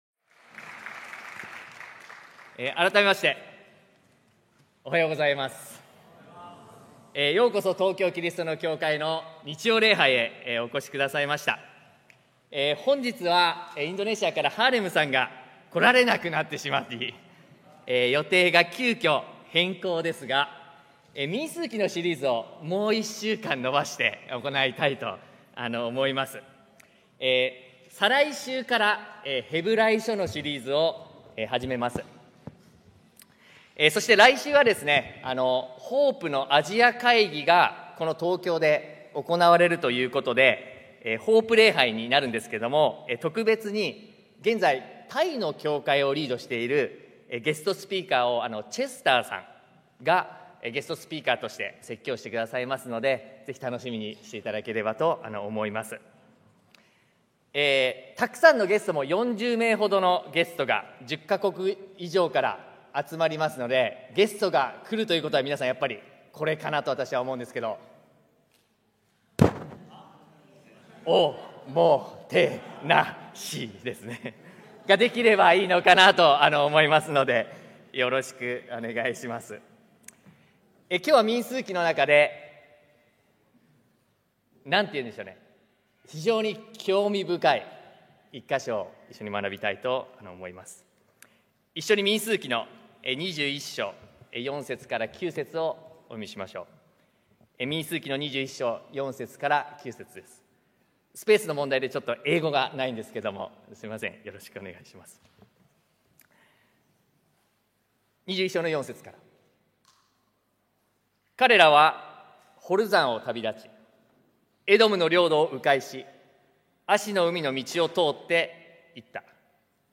日曜礼拝説教「キセキの関係